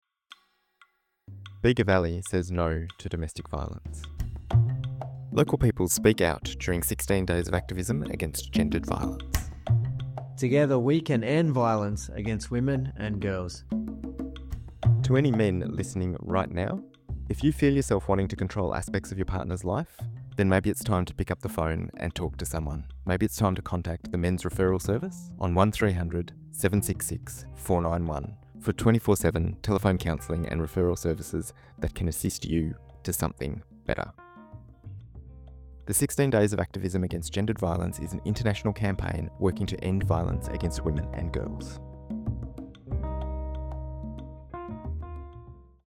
This year, the Bega Valley Domestic Violence & Sexual Assault Committee collected 25 statements from Bega Valley Community members, men and women from all walks of life, calling on all of us to do our part to stop Gender-Based Violence.
As part of this campaign, we collected brief audio statements from local Bega Valley community members to raise awareness about domestic, family, and sexual violence.